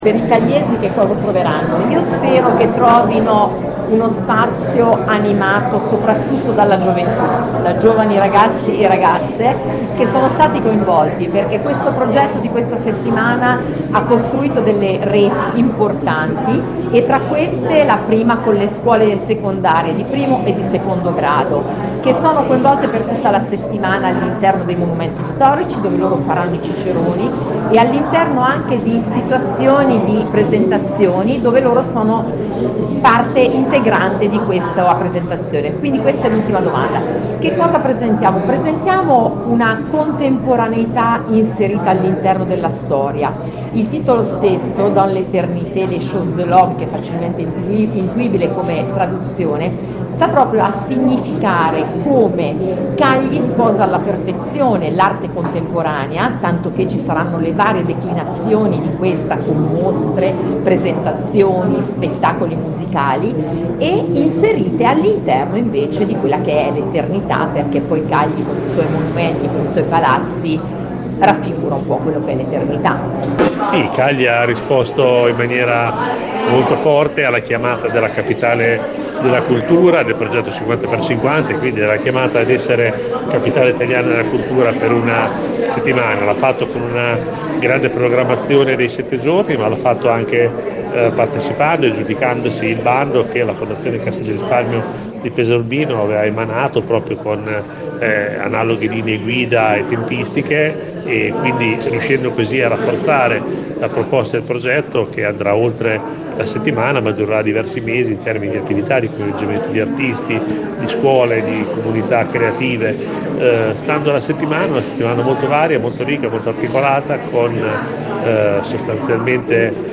La Settimana di Cagli – dal titolo ‘Dans l’Eternité les choses de l’homme’- si svolgerà dall’8 al 14 aprile con un ricco calendario. Ai nostri microfoni, ci spiegano i dettagli: Benilde Marini, Vice Sindaco del Comune di Cagli e Daniele Vimini, Assessore alla Bellezza del Comune di Pesaro.